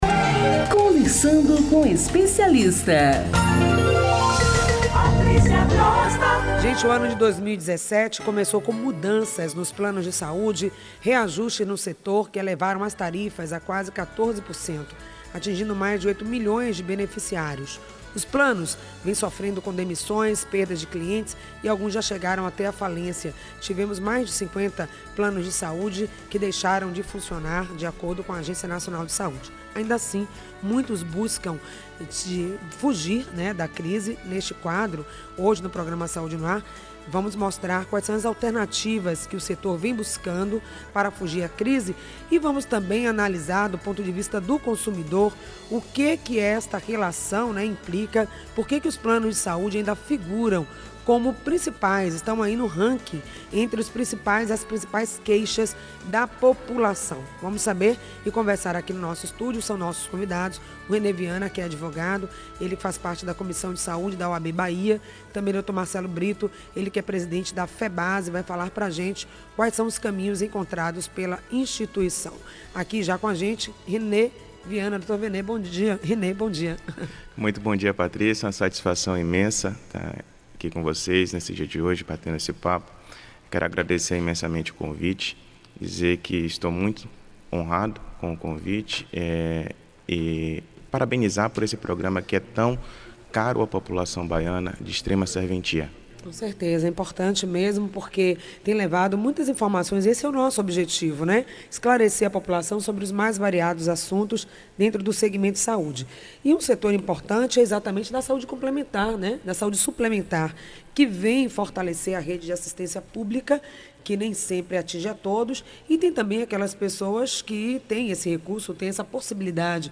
Em entrevista ao Saúde no Ar